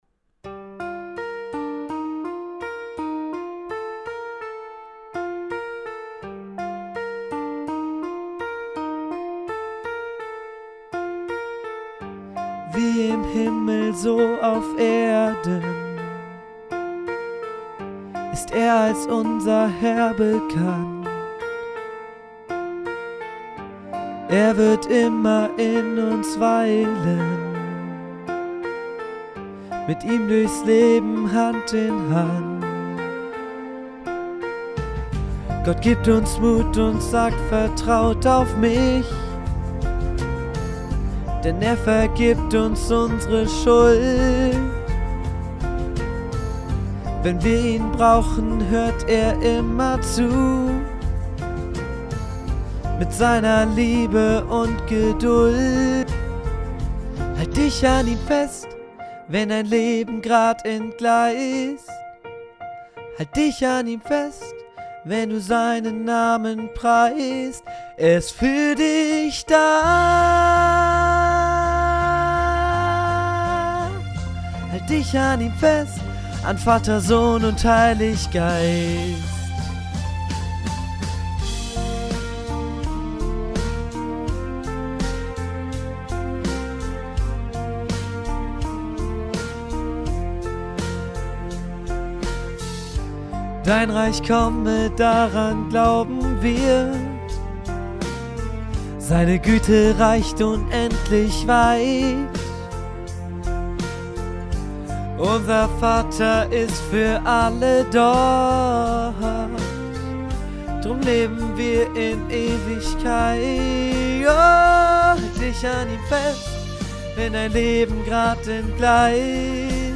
Sobald Melodie und Text stehen, wird das Ergebnis eingesungen, geschnitten und auf dem Vorstellungsgottesdienst präsentiert.